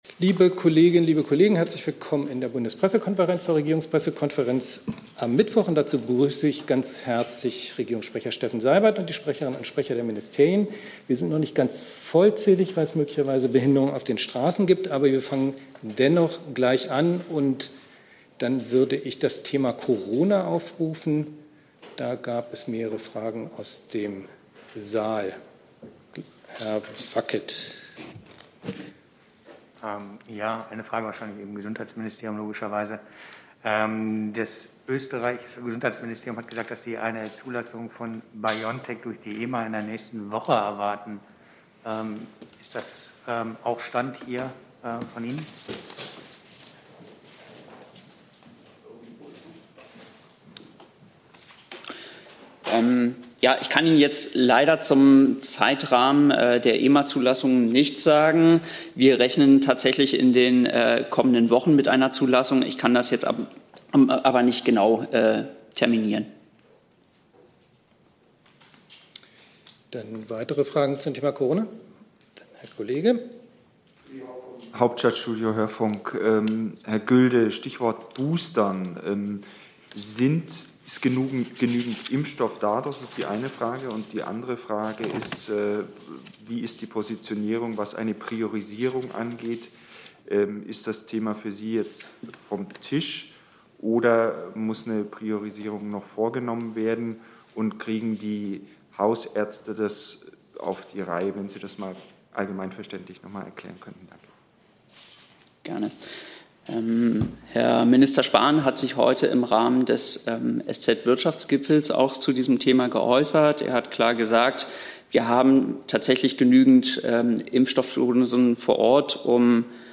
Regierungspressekonferenz in der Bundespressekonferenz Heute ohne naive Fragen.